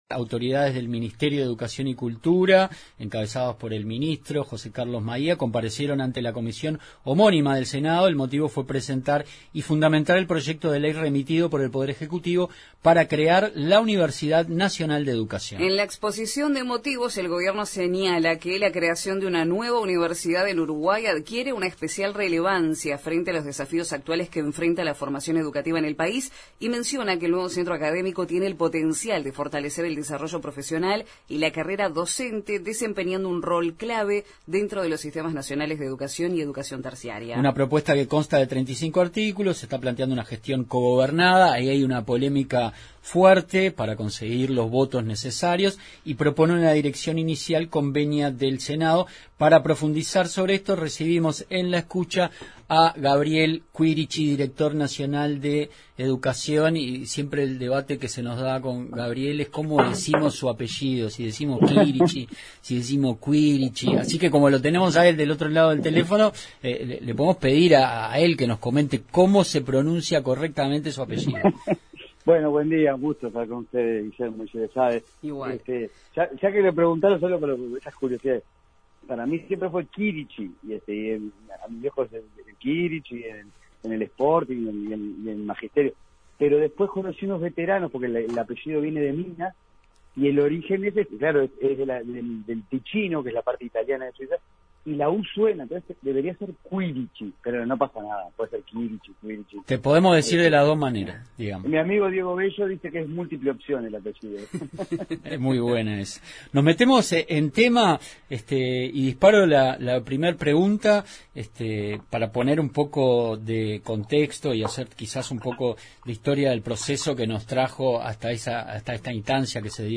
Entrevista a Gabriel Quirici, director nacional de Educación